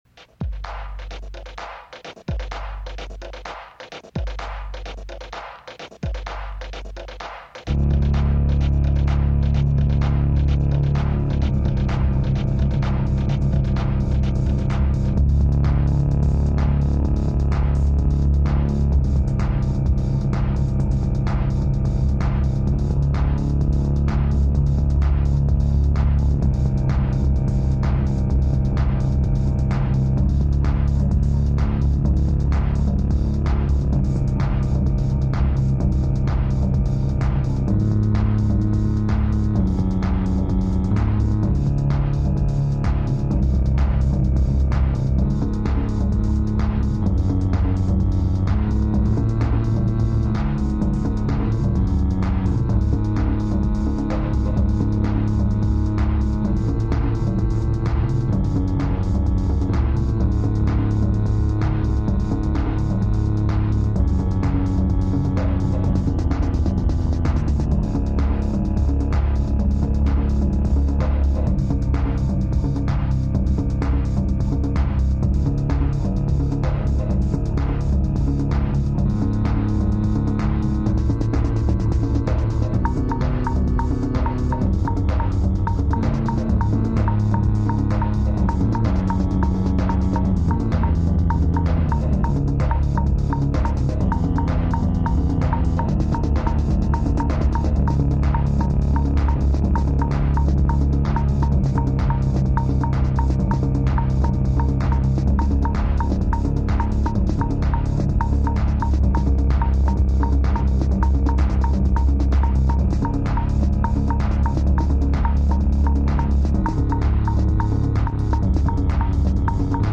Also recorded and mixed in Greenpoint in 1996